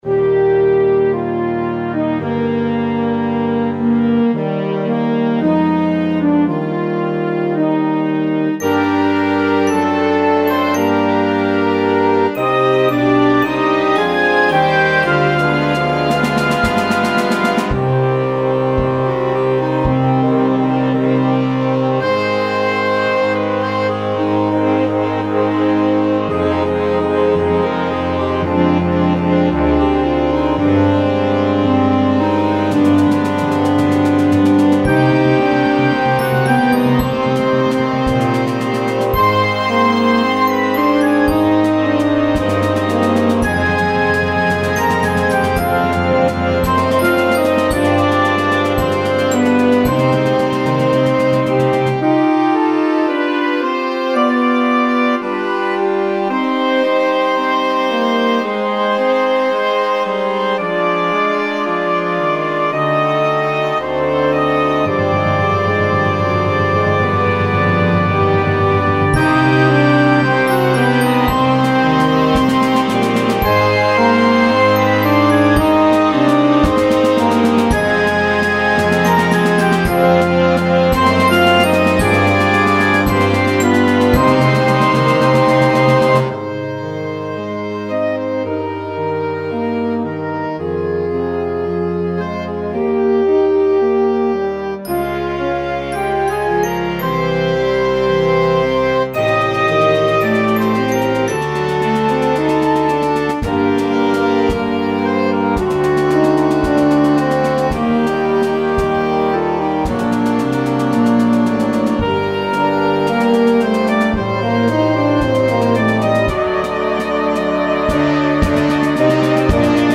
Répertoire pour Harmonie/fanfare - Marches militaires